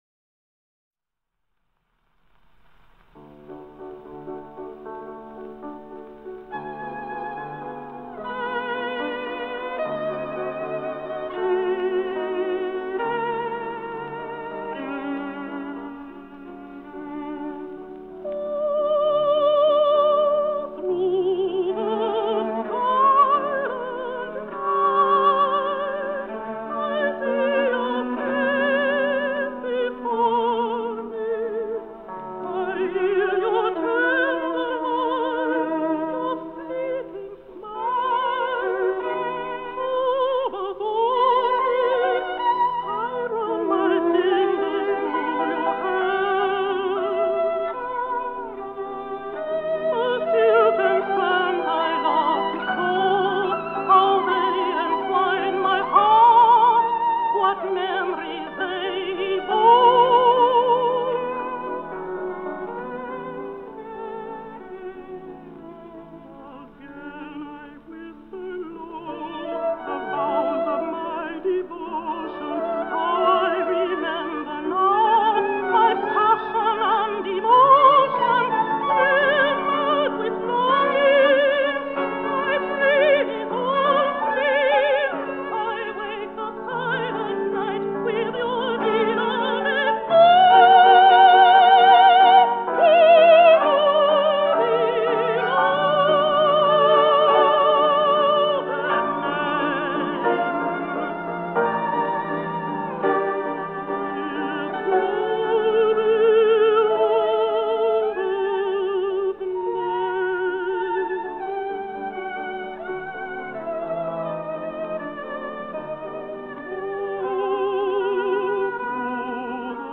Marian Anderson was an American contralto and one of the most celebrated singers of the twentieth century.
When Night Descends, Op. 4 (Recorded 1941).mp3